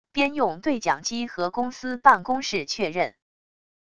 边用对讲机和公司办公室确认wav音频